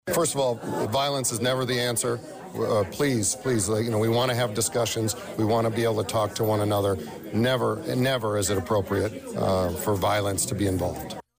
For Senator Faraci; he had spoken with CIMG on Monday at the CRIS Healthy Aging Center, very disturbed about the attempt on Former President Trump’s life.